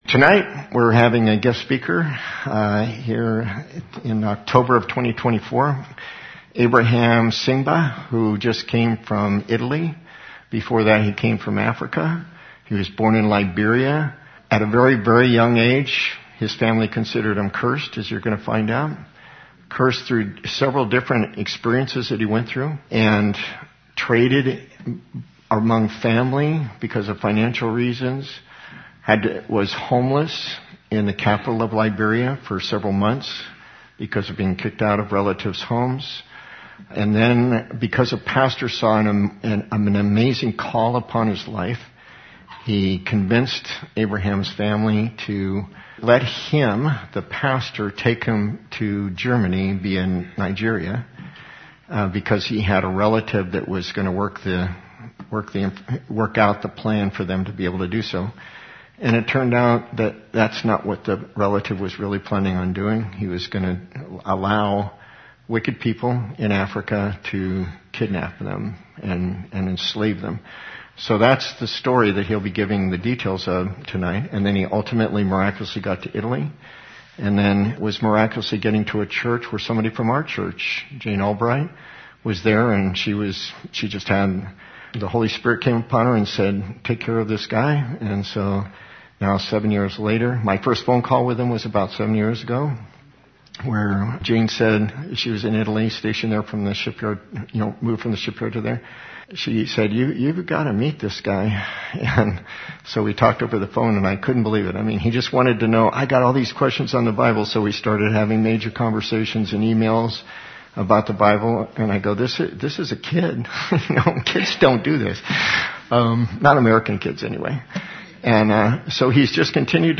There was hardly a dry eye as the attendees where intently listening to this very powerful testimony